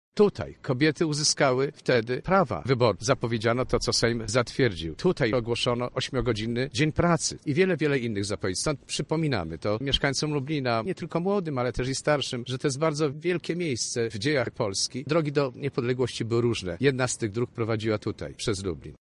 Ten gabinet wprowadził wiele zmian, które teraz uznajemy za oczywiste – mówi Stanisław Kieroński, wiceprzewodniczący Rady Miasta Lublin